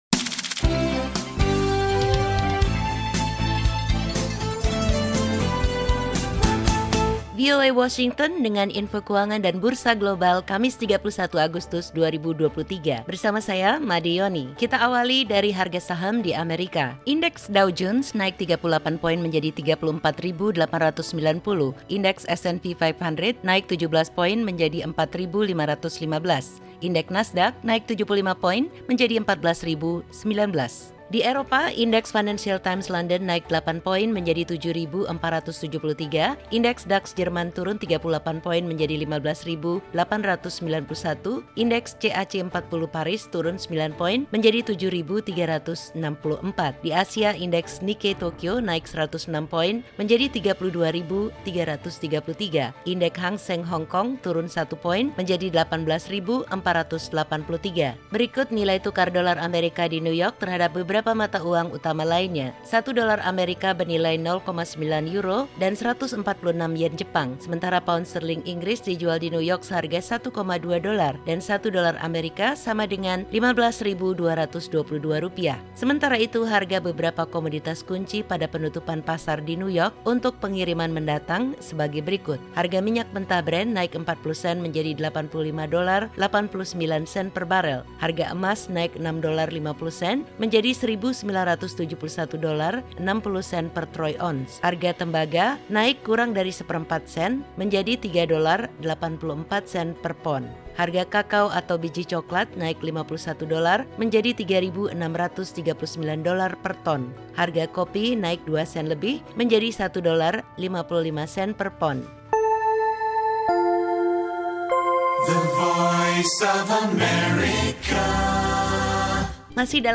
“Kita mendatangkan investasi asing ratusan miliar namun gaji pekerja masih sama dengan tahun 80an dan 90an. Ini tidak masuk akal,” kata Anwar, di hadapan hadirin di auditorium yang penuh sesak.